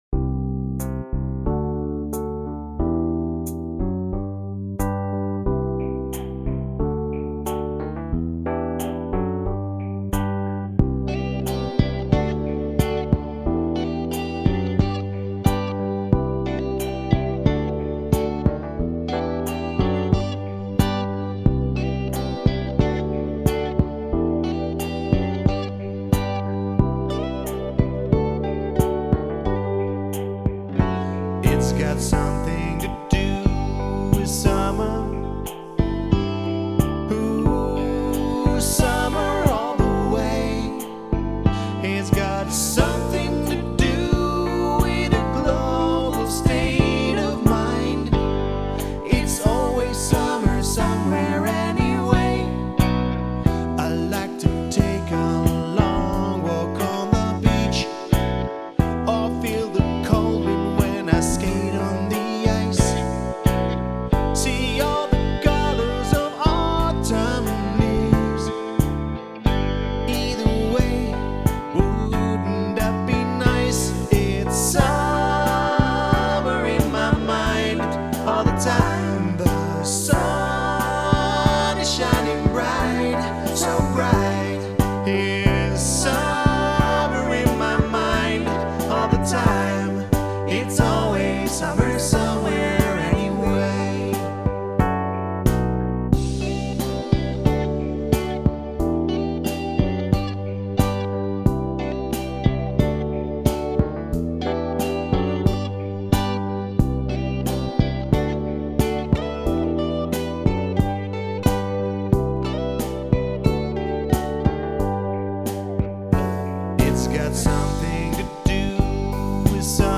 Begonnen in de vorm van een jamsessie